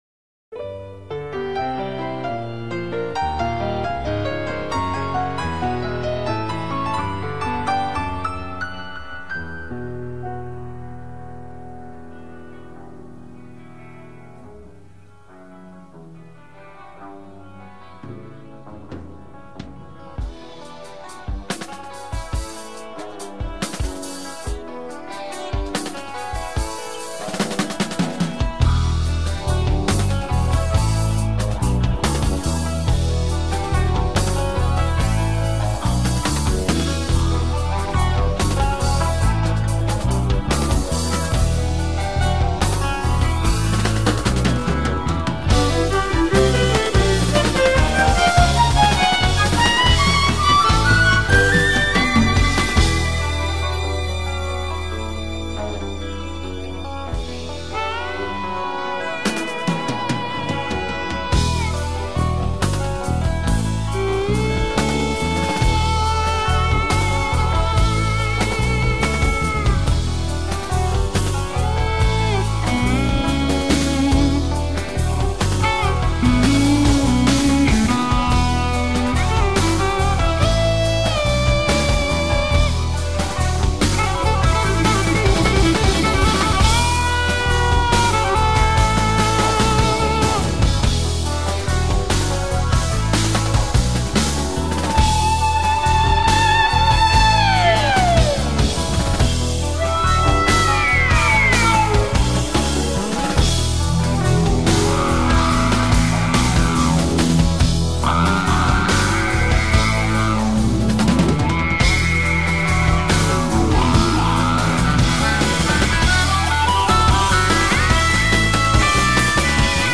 Choice Prog Rock Goodness